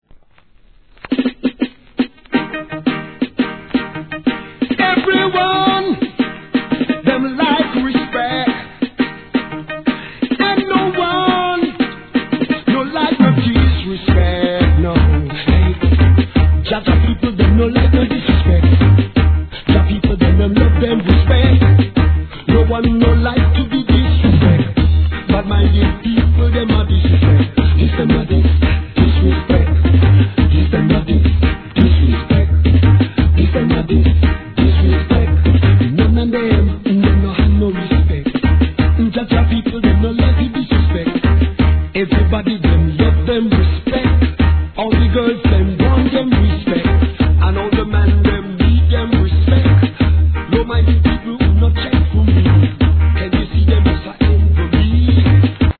REGGAE
好DANCE HALLリズム!!